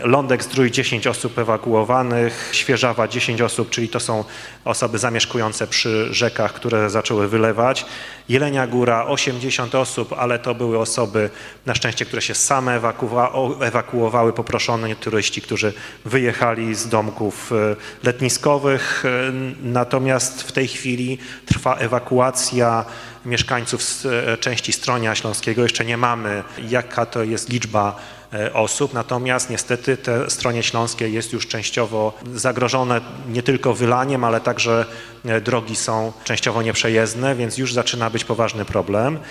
Maciej Awiżeń poinformował też jak w sobotę, do godz. 20:30 wyglądała sytuacja z ewakuacją mieszkańców.